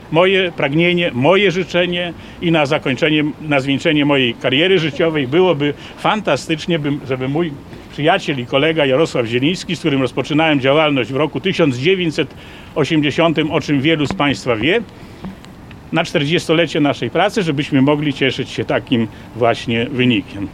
Spotkanie prowadził Romuald Łanczkowski, radny wojewódzki PiSu, który podkreślał, że kandydatem Prawa i Sprawiedliwości w tej części województwa jest Jarosław Zieliński, poseł i wiceminister MSWiA. Jak dodał, celem maksimum w wyborach jest większość konstytucyjna.